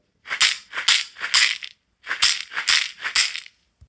ひょうたん底 バスケットマラカス アフリカ 民族楽器 （p505-33） - アフリカ雑貨店 アフロモード
ブルキナファソで作られたバスケット素材の素朴なマラカスです。水草とひょうたんと木の実でできています。やさしいナチュラルな乾いた音を出します。